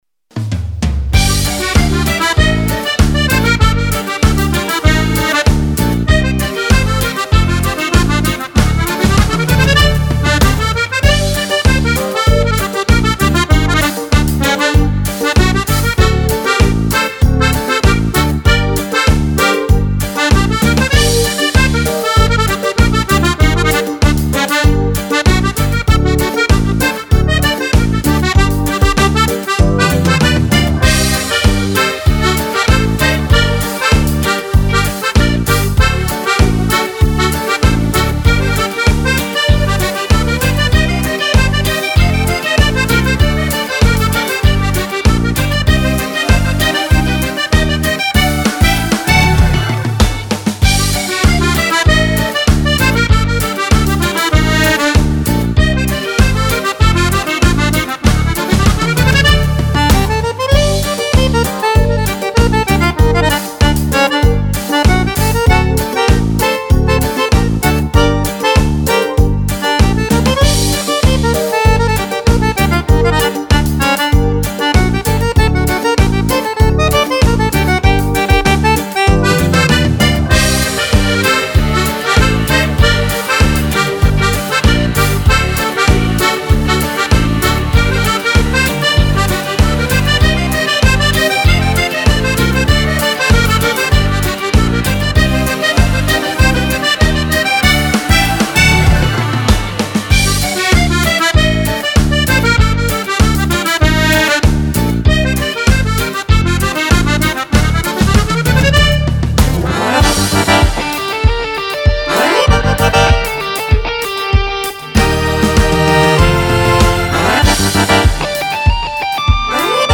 Cumbia per Fisarmonica
Fisarmonica